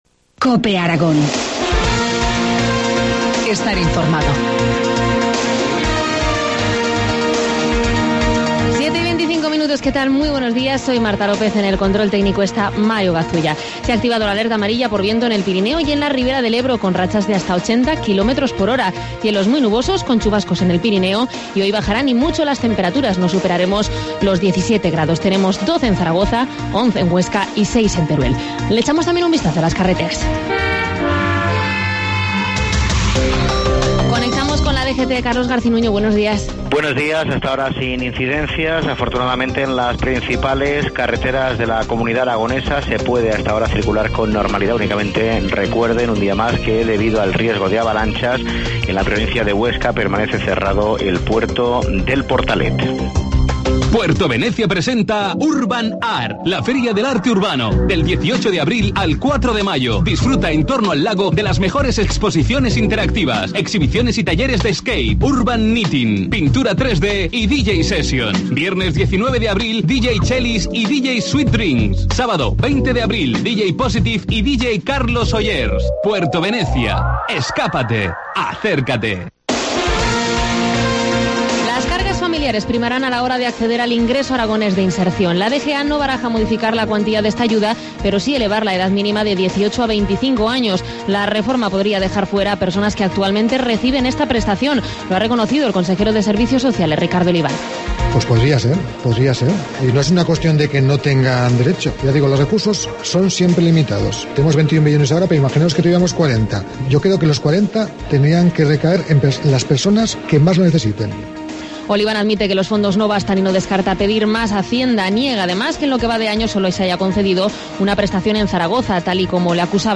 Informativo matinal, viernes 19 de abril, 7.25 horas